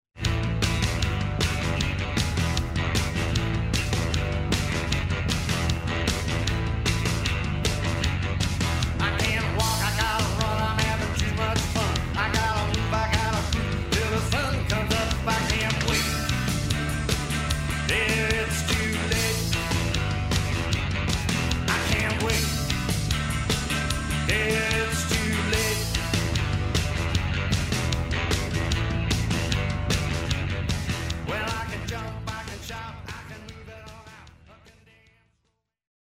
Rocking, cajun style of music
playing fiddle and lap steel guitar.